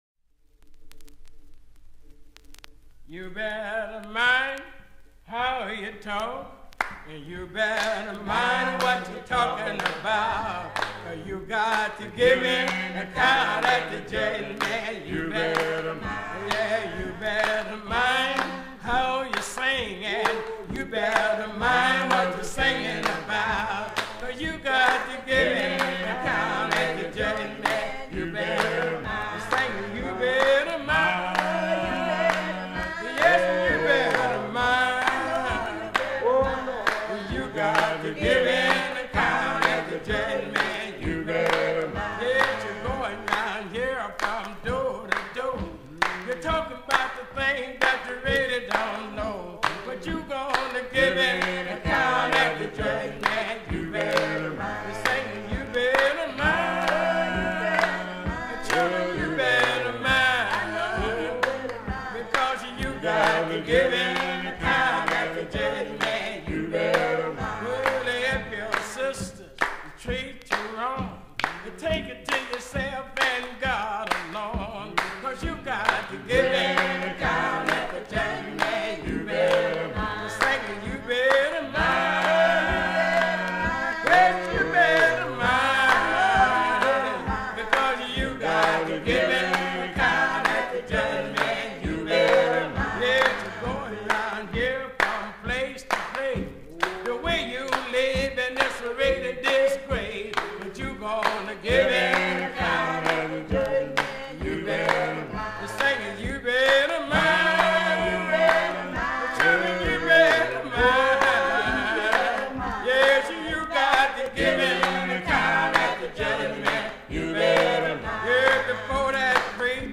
They are rich and sonically dense meaning that there’s a variety of sounds to choose from. I’ve chosen to begin experimenting with the track ‘You Better Mind’. While it only consists of vocals and clapping it contains a lot of depth.
bessie-jones.mp3